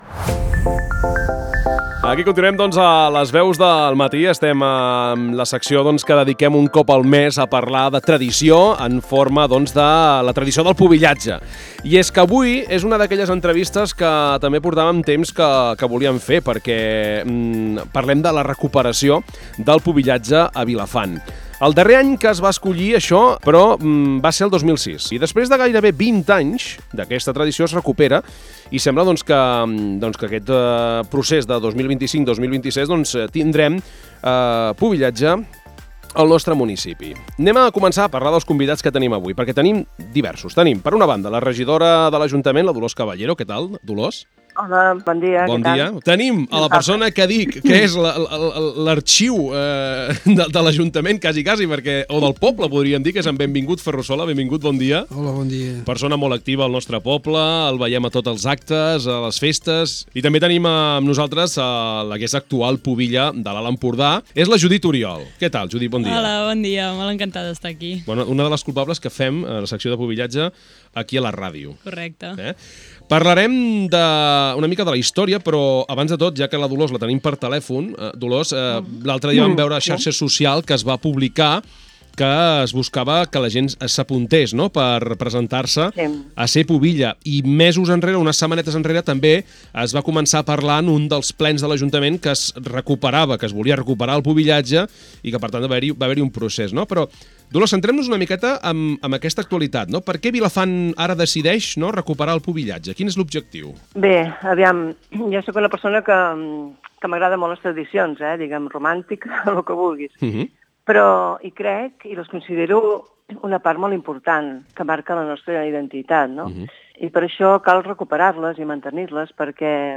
En Benvingut Ferrusola, exregidor de l’Ajuntament i persona clau en l’organització del pubillatge durant molts anys, ens ha explicat com es vivia aquella època.
Per entendre el present i el futur del projecte, hem conversat amb Dolors Caballero, actual regidora de Cultura, que ens ha detallat els motius que han portat el consistori a recuperar aquesta tradició. Caballero destaca la voluntat de tornar a donar valor a les arrels, reforçar la identitat local i oferir un espai de participació al jovent del poble.